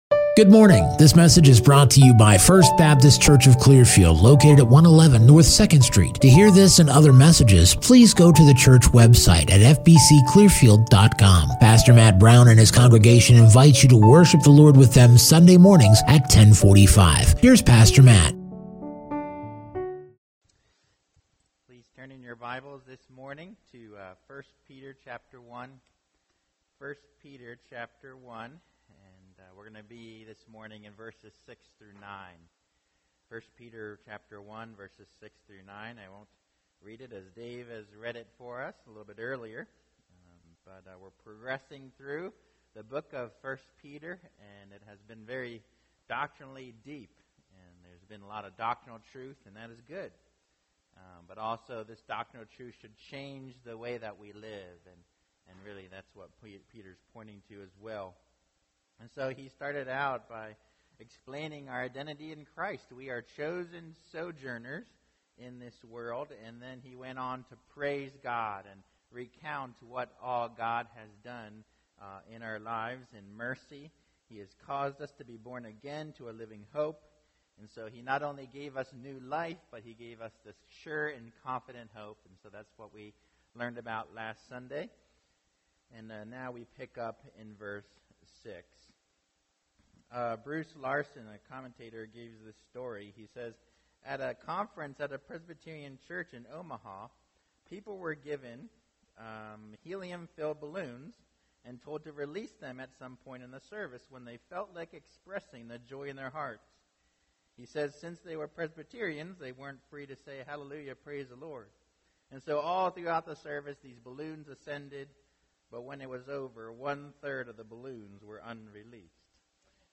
2021 Rejoice in Hope Preacher